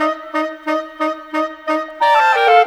Rock-Pop 07 Winds 05.wav